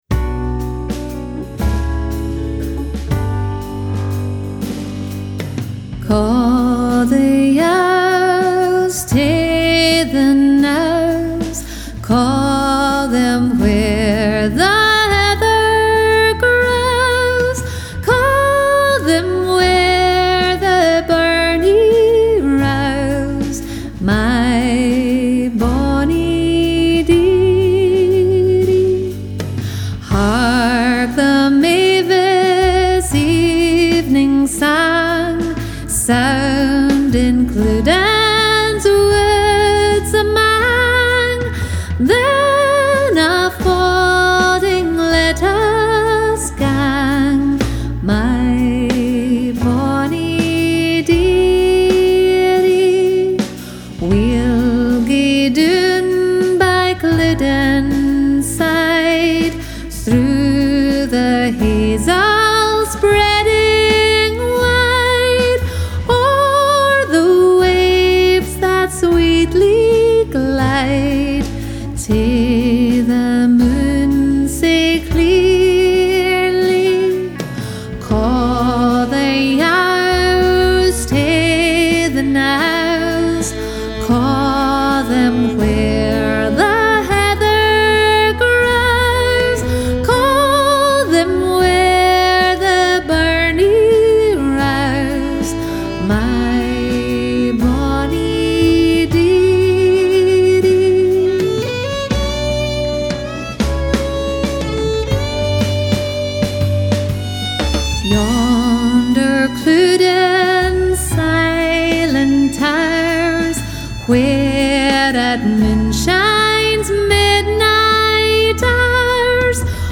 Scottish Music Download Ca' the Yowes tae the Knowes MP3